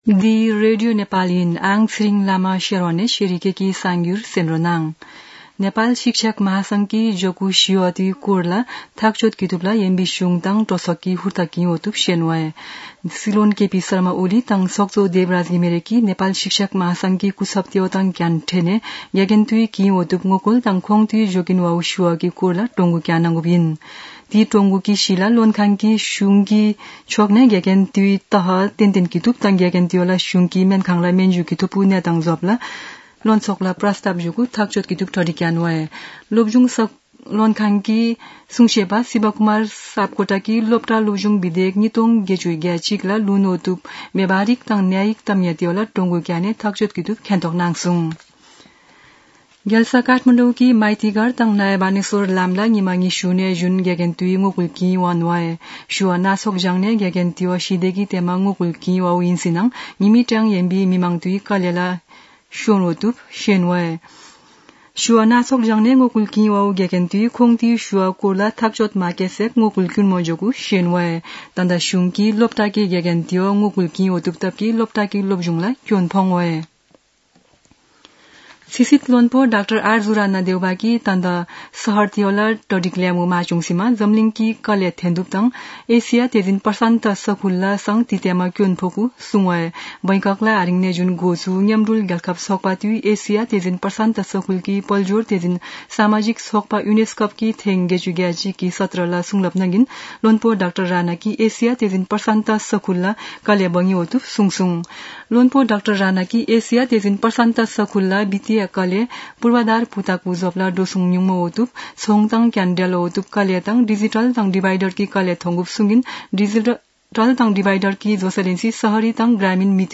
शेर्पा भाषाको समाचार : ८ वैशाख , २०८२
sharpa-news-1-1.mp3